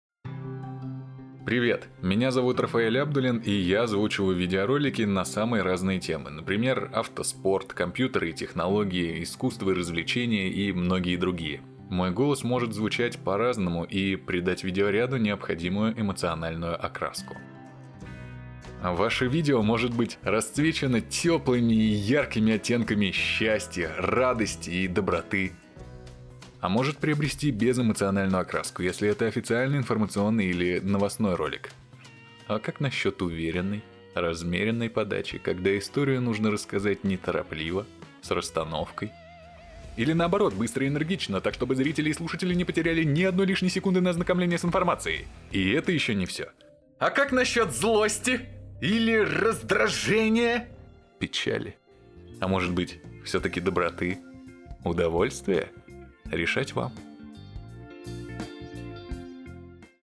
Демо голоса